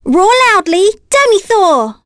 Ophelia-Vox_Skill4.wav